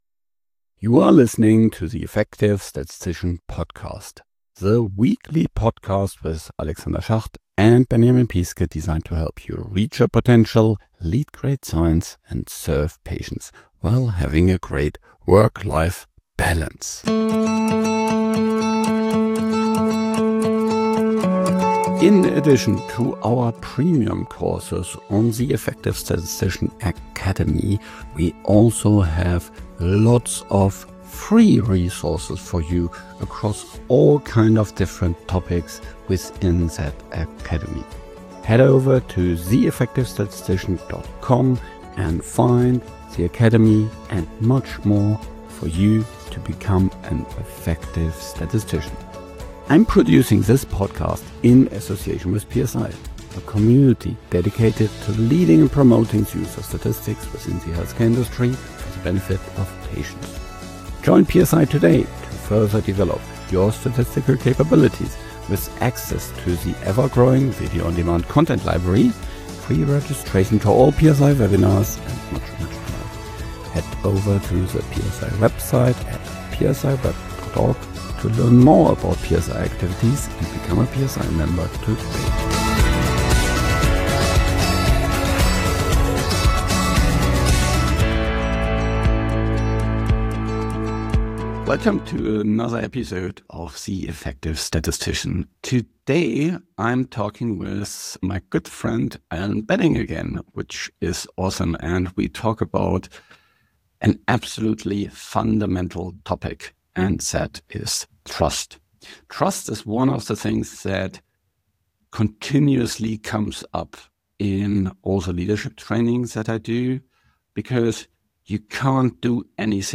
This conversation goes well beyond theory. We focus on practical use cases, real limitations, and how statisticians, programmers, and data scientists can responsibly use GenAI to become more effective.